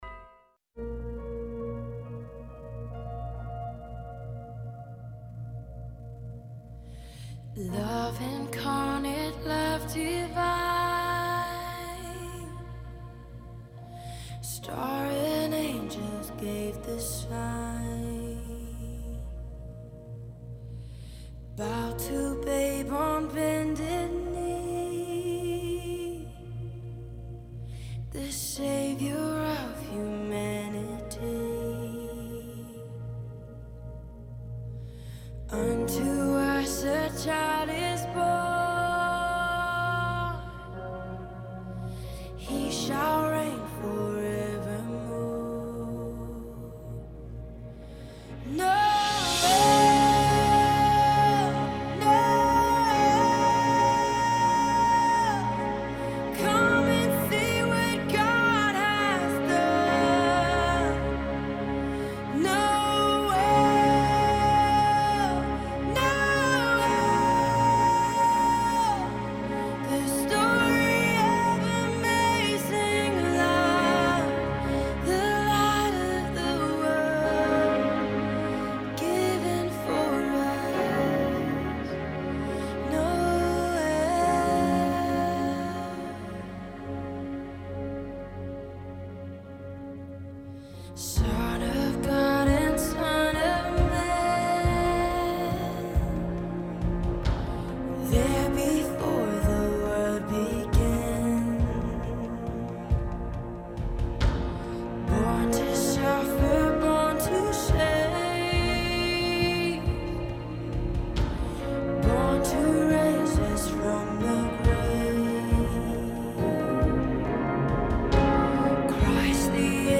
0:43 - 0:60: Attempted terror attack averted in New York. Callers weigh in.